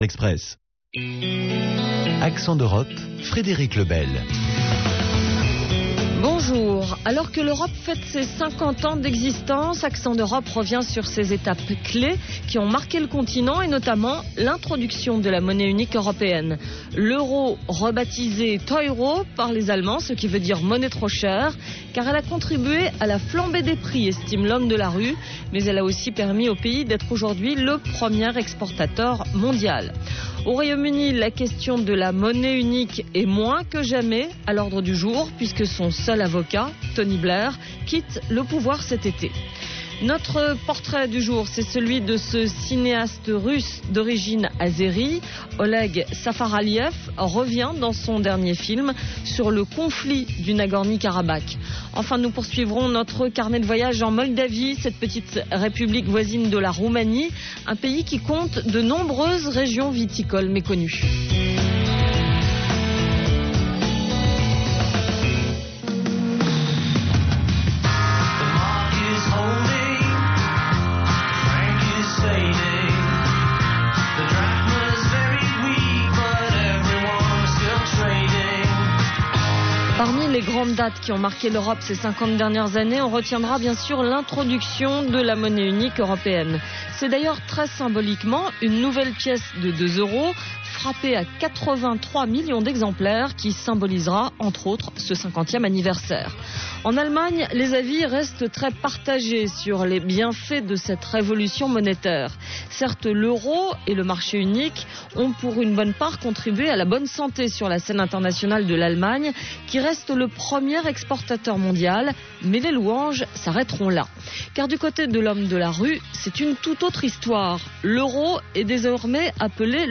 Reportage : Alors que 80 000 pigeons vivent dans la capitale, la Mairie de Paris vient d'inaugurer le premier des 6 pigeonniers prévus d'ici la fin de l'année.